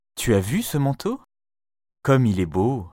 les sons [ o ] bureau, tôt [ ɔ ] bonne, prof